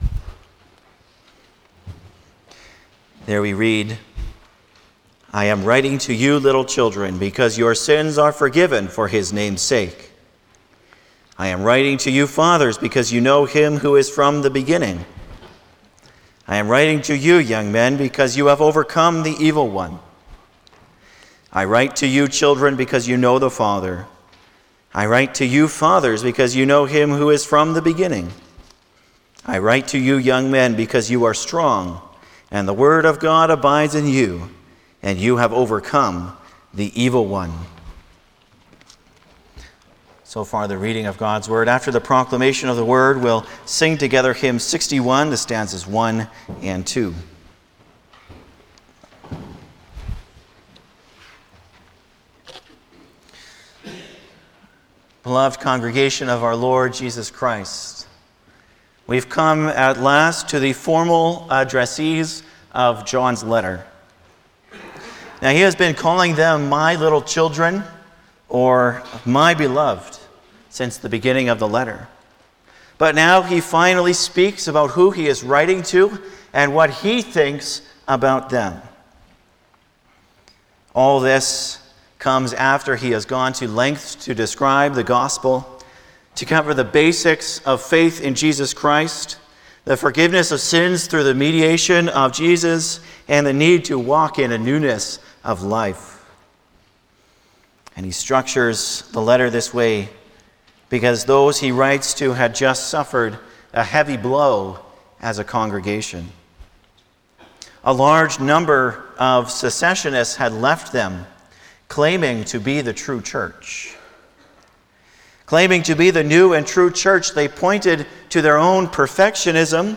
Passage: 1 John 2:12-14 Service Type: Sunday morning
08-Sermon.mp3